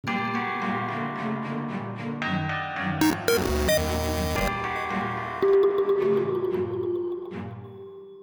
• Качество: 320, Stereo
без слов
инструментальные
тревожные
электронные